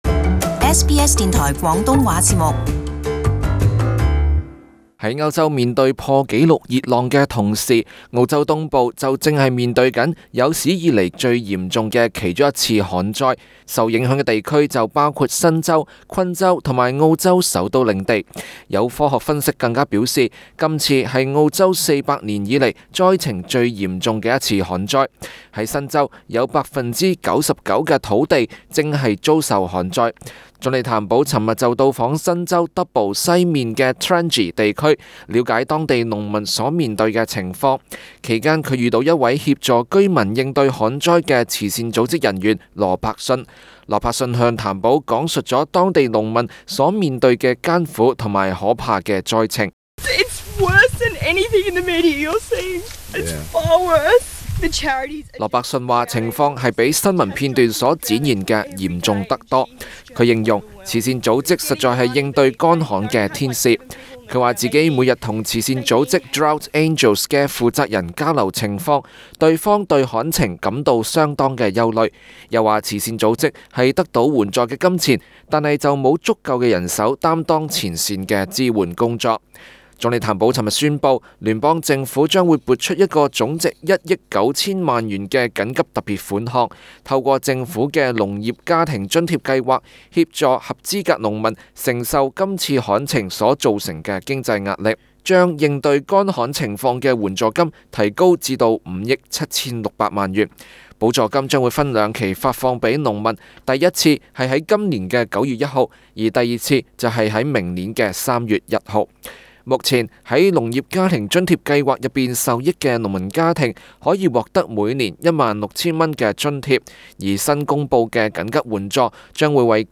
【時事報導】政府急撥兩億應對世紀旱災